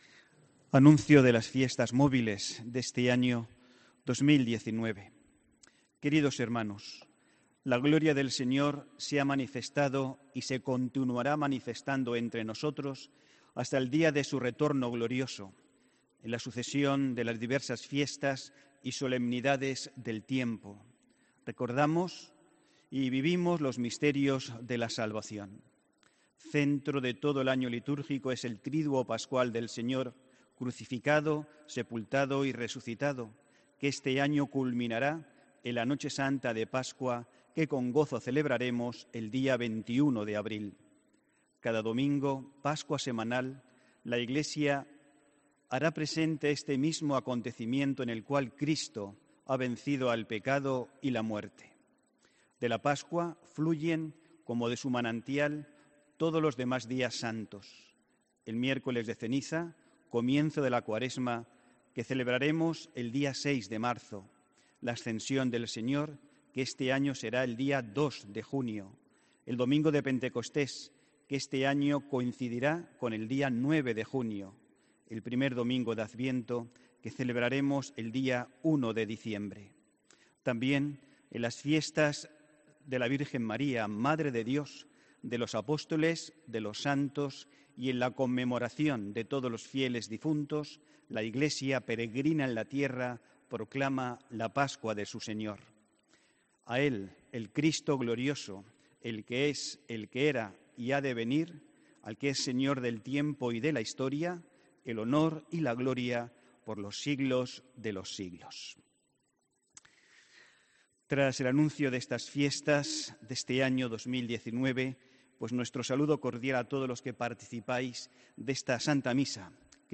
HOMILÍA 6 ENERO DE 2019